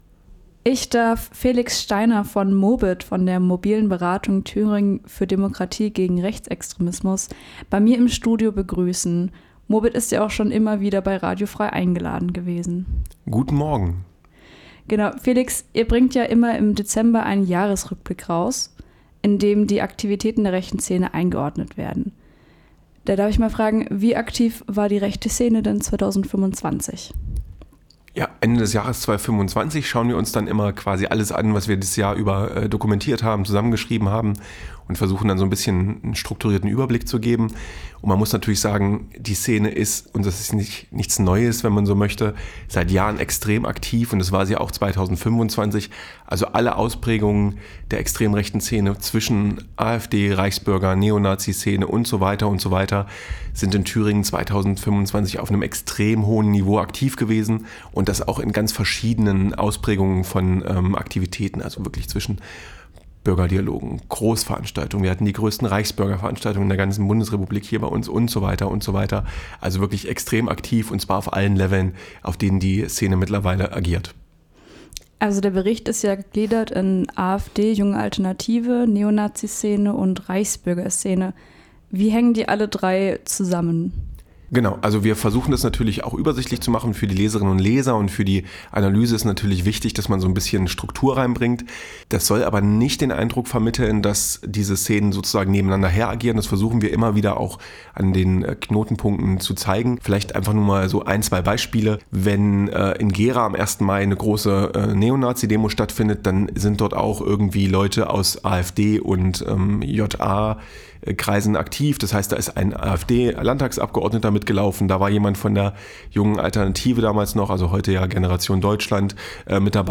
2025 im Blick - Jahresrückblick zu extrem rechten Aktivitäten in Thüringen | Interview mit Mobit